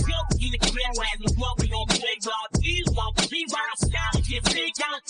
rap_sample.mp3